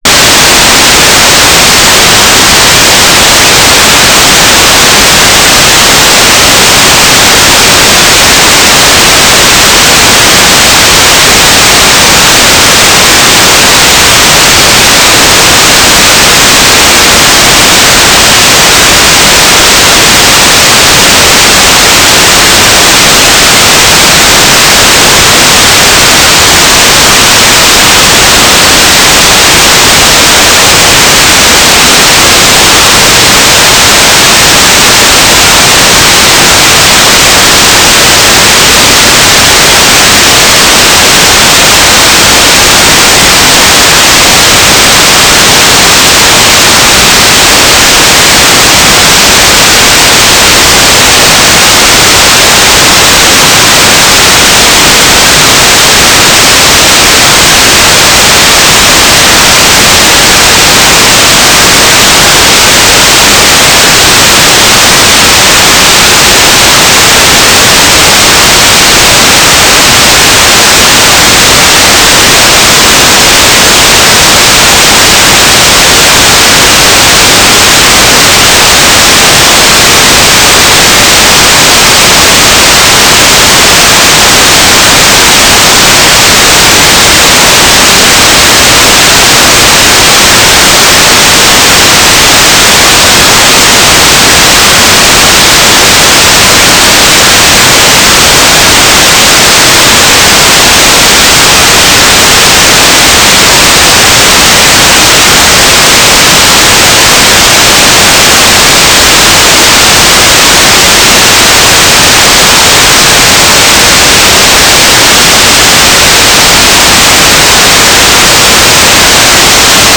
"transmitter_mode": "GMSK",